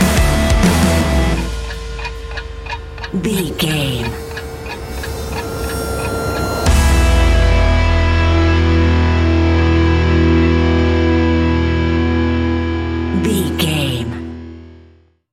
Ionian/Major
hard rock
instrumentals